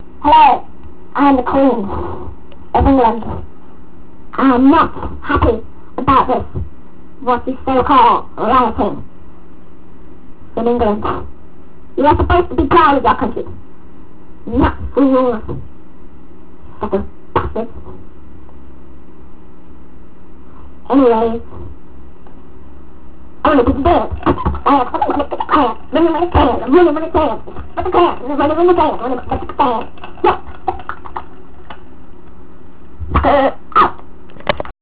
The great Queen's speeches.
Queens speech (volume 2)